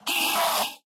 should be correct audio levels.
scream1.ogg